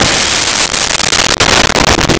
Bass Drum Cymbal Hit 05
Bass Drum Cymbal Hit 05.wav